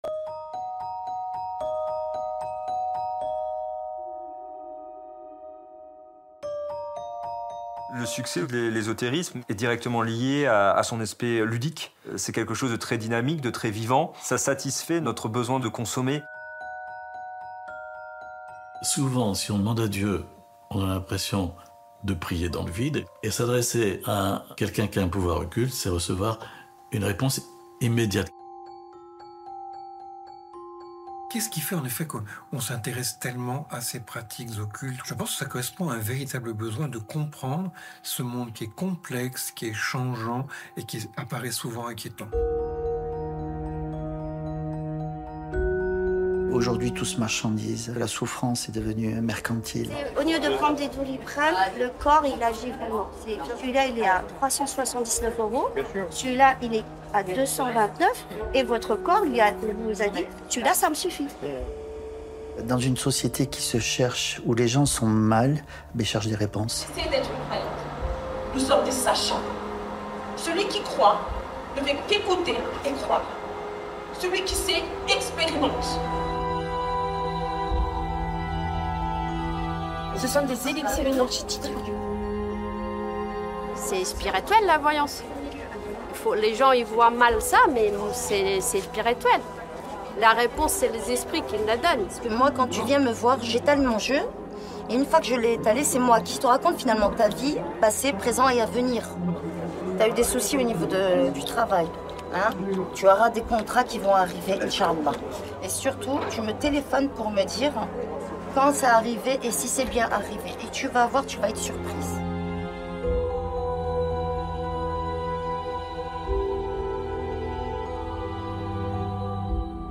La bande son La majorité des informations sont dans la bande son. On peut donc se contenter d'écouter ce documentaire comme à la radio.